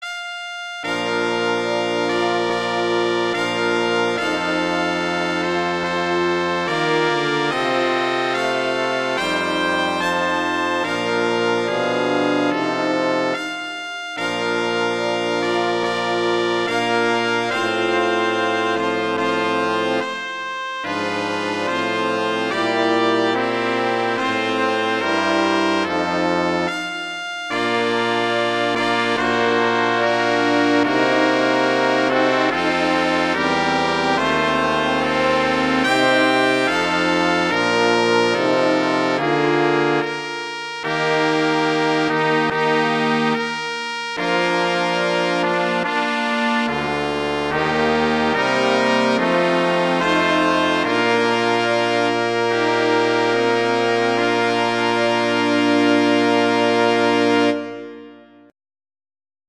arrangements for brass quintet